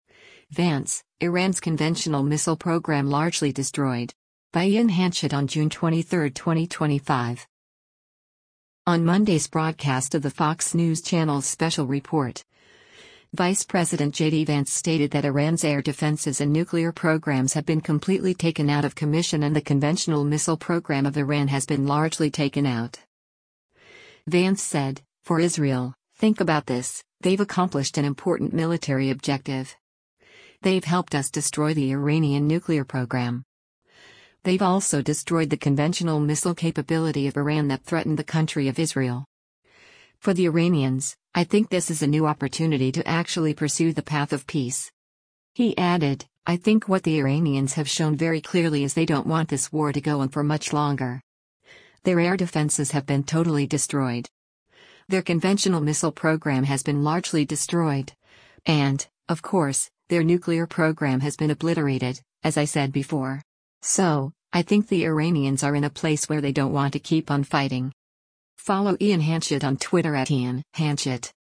On Monday’s broadcast of the Fox News Channel’s “Special Report,” Vice President JD Vance stated that Iran’s air defenses and nuclear programs have been completely taken out of commission and the conventional missile program of Iran “has been largely” taken out.